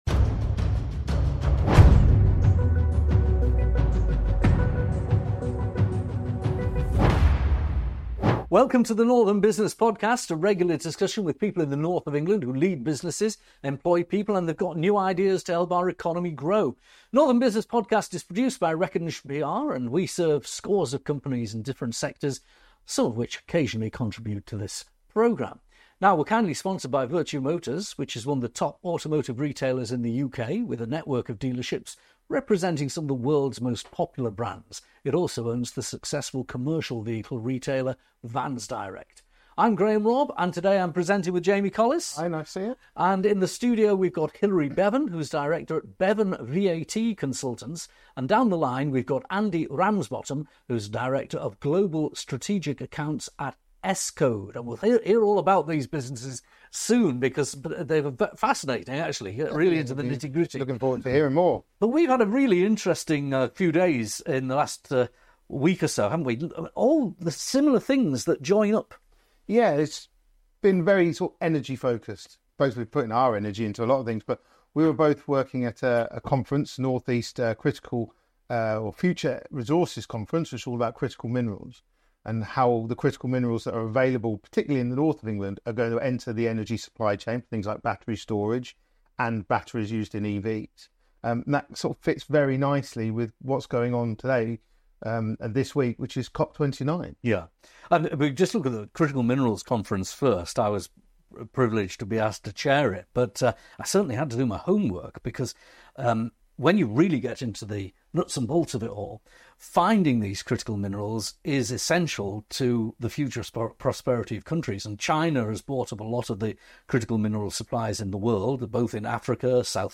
Welcome to the Northern Business Podcast, a regular discussion with people in the North of England who lead businesses, employ people and have new ideas to help our economy grow.